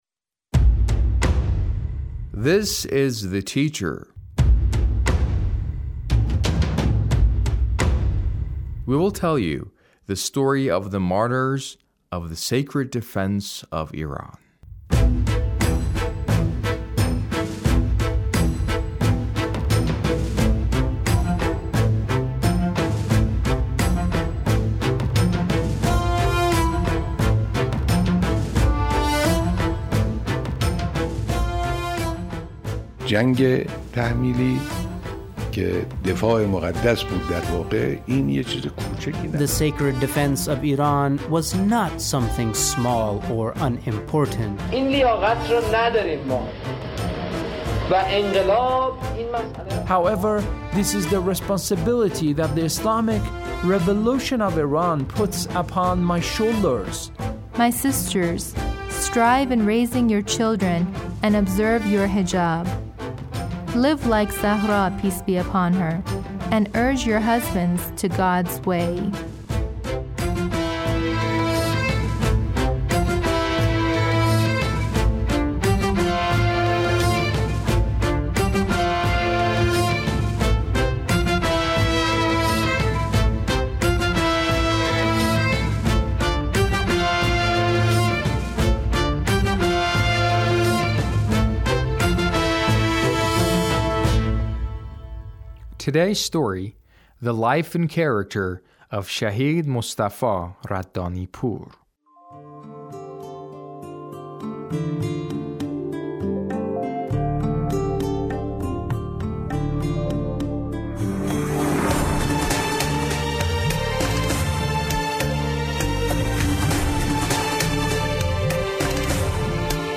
A radio documentary on the life of Shahid Mostafa Raddanipour- Part 2